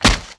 WAV · 17 KB · 單聲道 (1ch)